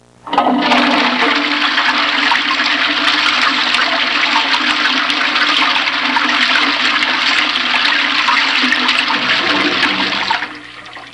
Flush Toilet Sound Effect
Download a high-quality flush toilet sound effect.
flush-toilet-1.mp3